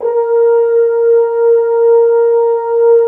Index of /90_sSampleCDs/Roland LCDP06 Brass Sections/BRS_F.Horns 2 _/BRS_FHns Dry _